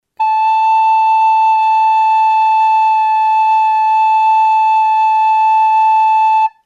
--------------------------------------------------------------------------------------------------------------------------------------------- A nota LA. Tapa o burato traseiro da túa frauta e os dous buratos superiores da parte dianteira segundo este esquema. Nota La